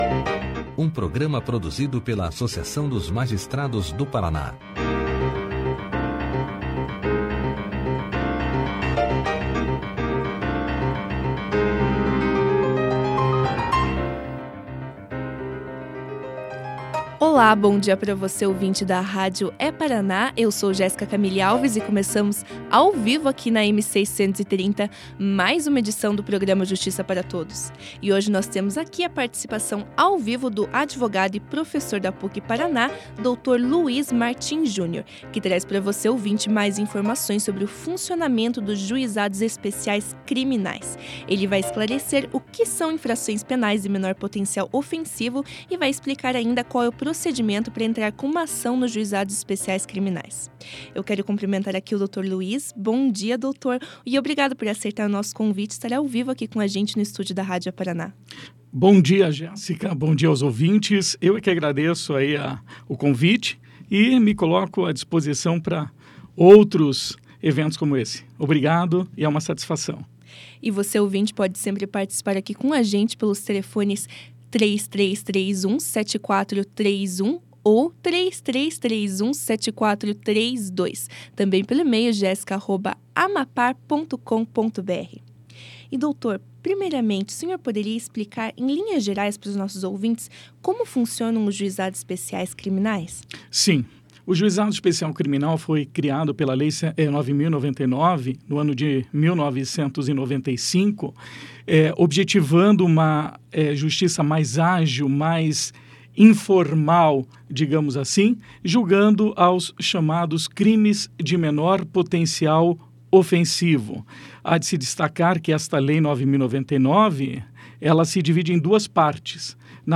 Além disso, atualmente, o juizado especial criminal está permitindo, muitas vezes, uma situação positiva para os envolvidos por meio da conciliação”, ressaltou o professor durante a entrevista.